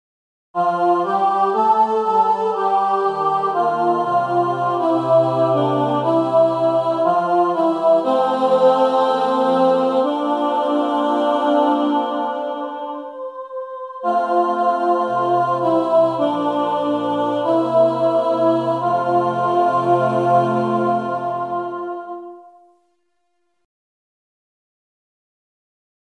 Key written in: F Major